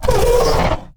CosmicRageSounds / wav / general / combat / creatures / ryuchi / she / hurt2.wav
hurt2.wav